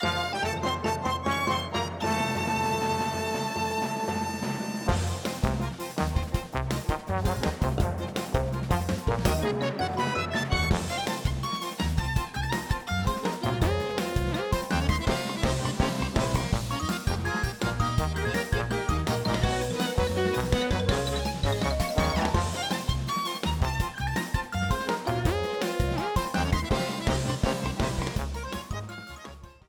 Ripped from game